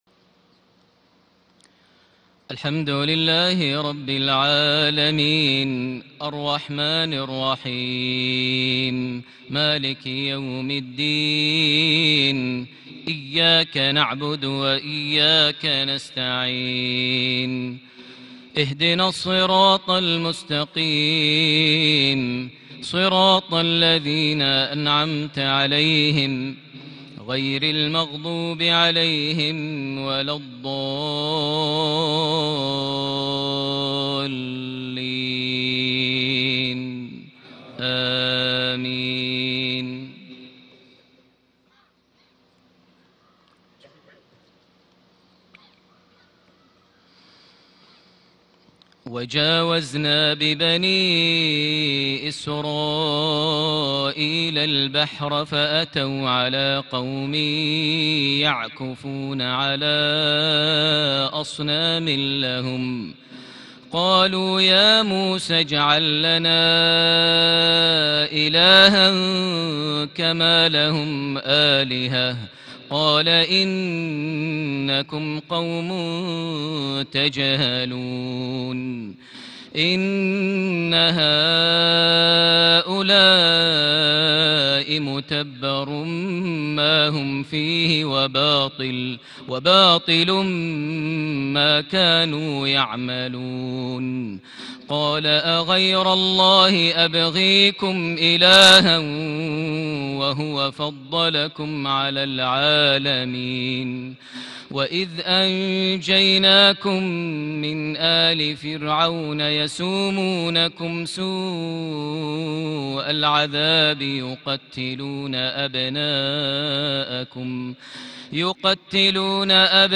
صلاة العشاء ٢٥ محرم ١٤٤٠هـ من سورة الأعراف (١٤٧-١٣٨) > 1440 هـ > الفروض - تلاوات ماهر المعيقلي